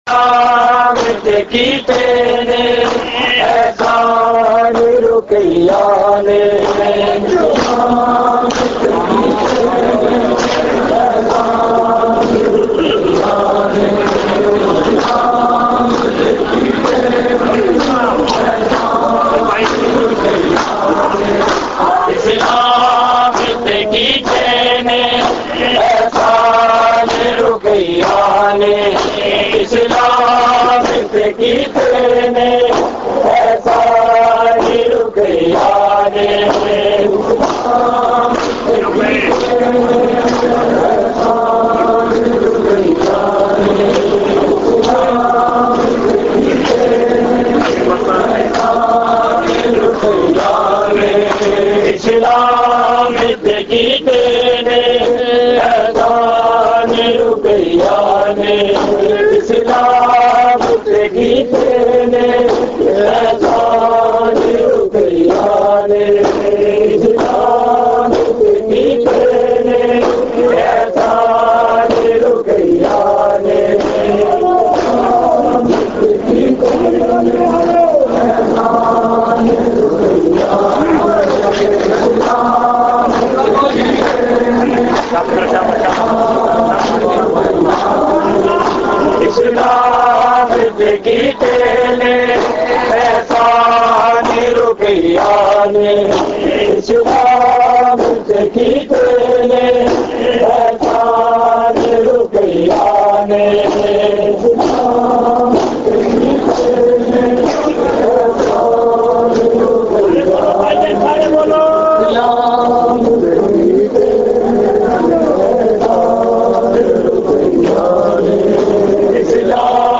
Markazi Matmi Dasta, Rawalpindi
Recording Type: Live